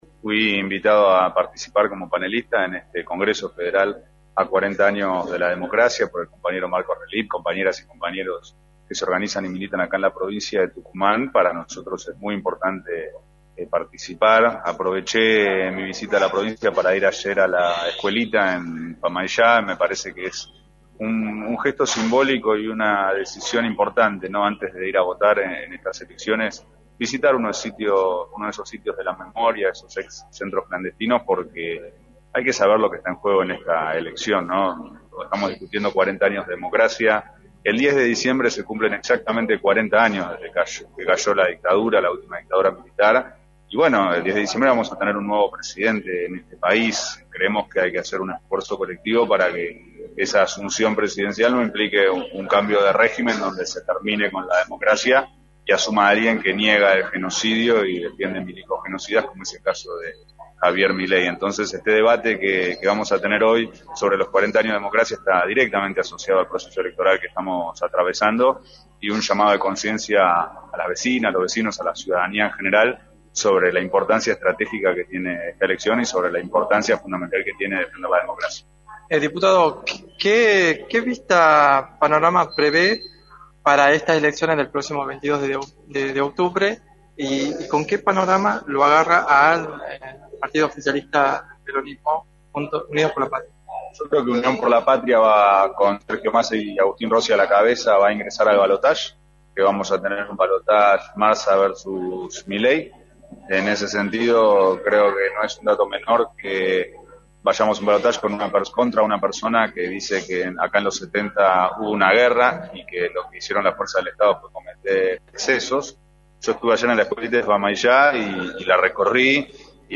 Juan Marino, Diputado Nacional, fue invitado a participar como panelista en el Congreso Federal, a 40 años de la Democracia y remarcó en Radio del Plata Tucumán, por la 93.9, los ejes de este congreso.